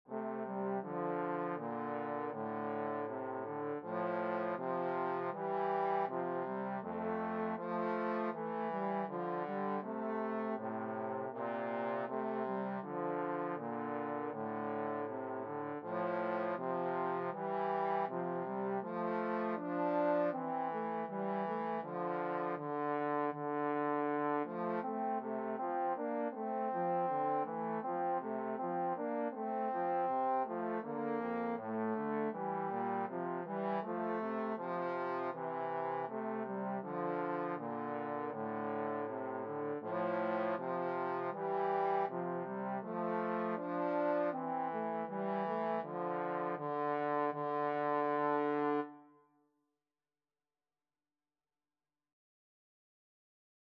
4/4 (View more 4/4 Music)
Andante Espressivo = c. 80
Trombone Duet  (View more Intermediate Trombone Duet Music)